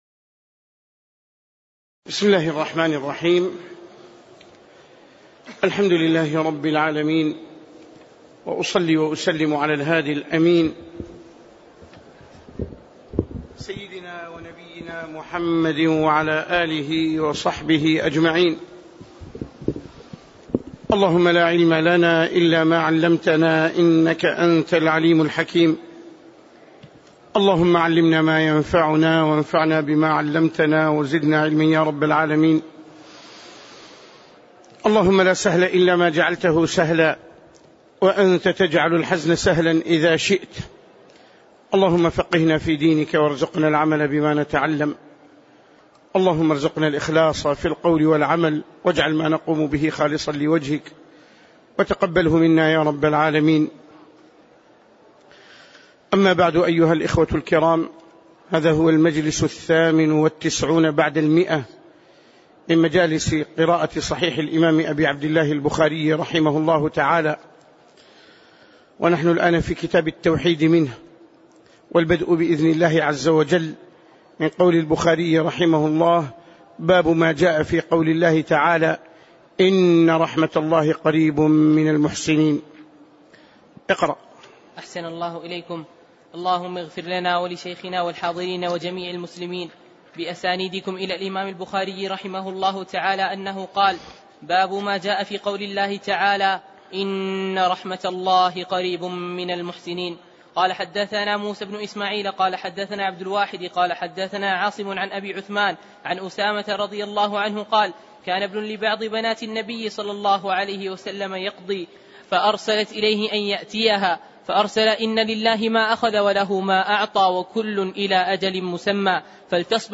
تاريخ النشر ١٥ جمادى الأولى ١٤٣٩ هـ المكان: المسجد النبوي الشيخ